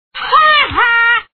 Ha Ha Sound Effect Free Download
Ha Ha